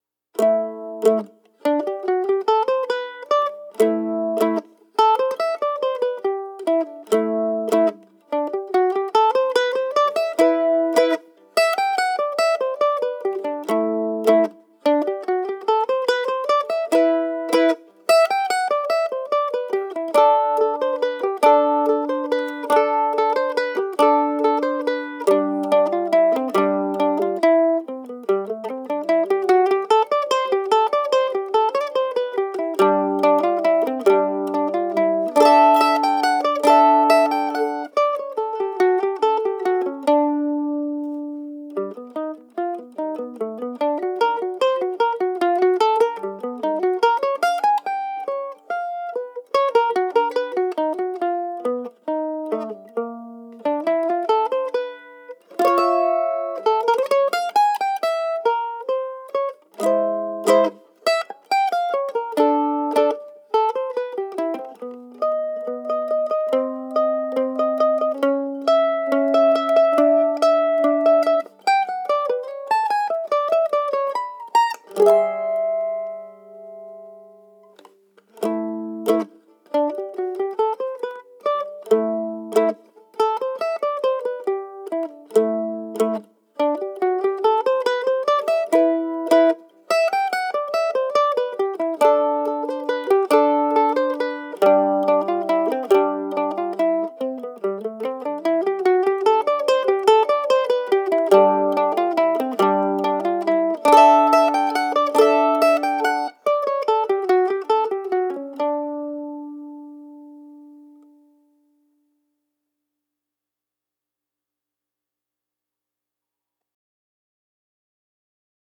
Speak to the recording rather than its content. for solo mandolin) CD at Wild Sound Studio.